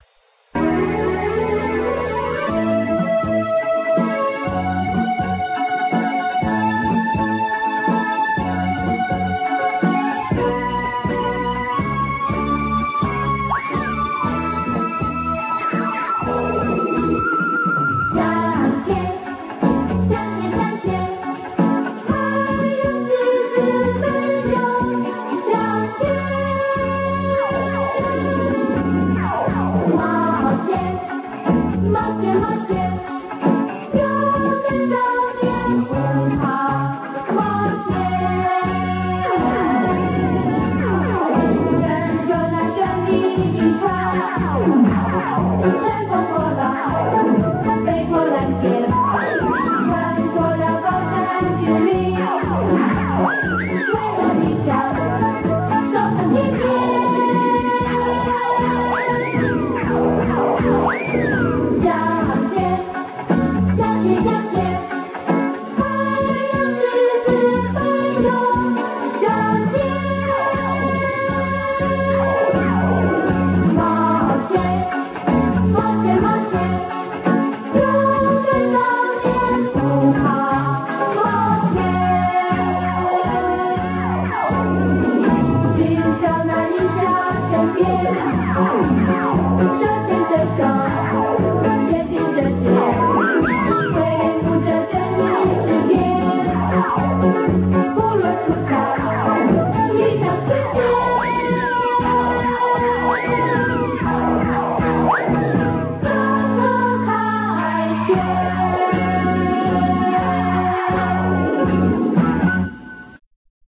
主題曲很好聽，很有燦爛陽光的味道，而艾斯迪的樂觀、堅強和朋友間的友情以及